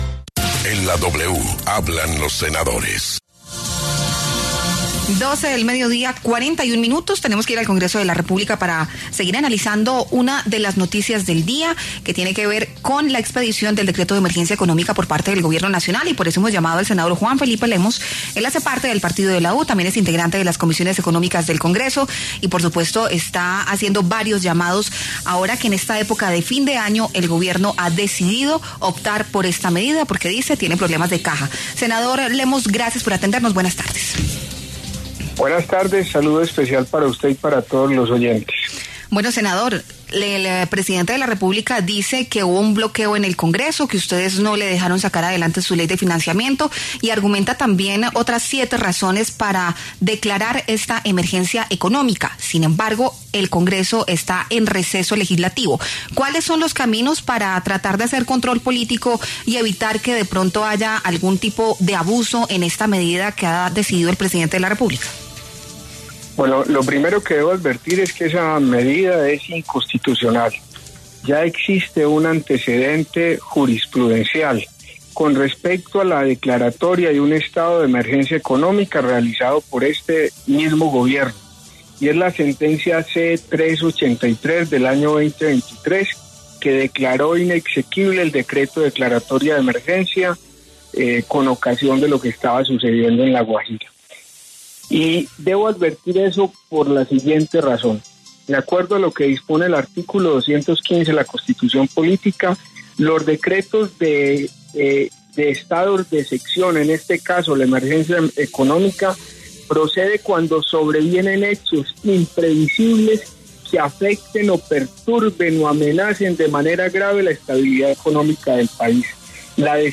El senador Juan Felipe Lemos habló en La W a propósito del decreto de emergencia económica del Gobierno del presidente Gustavo Petro.